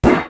clang2.ogg